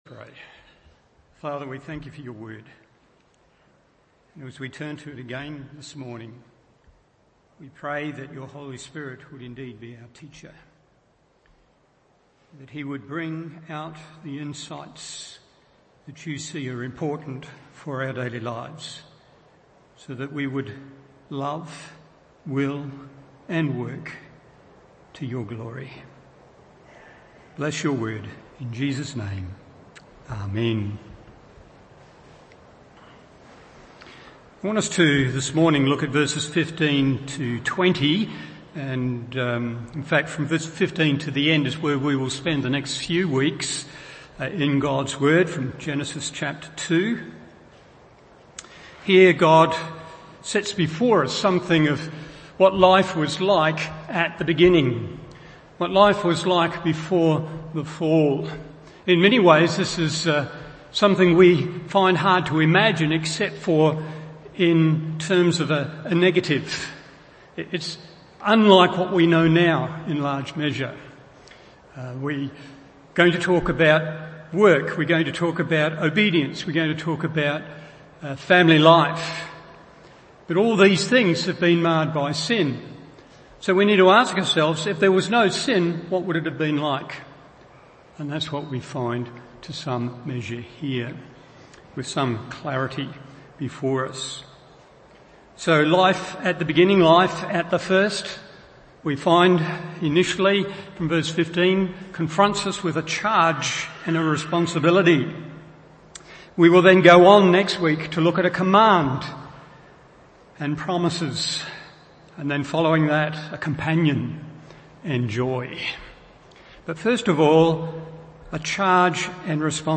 Morning Service Genesis 2:15-20 1. Life Consisted in Work 2. And in Exercising Authority 3. To the Glory Of God…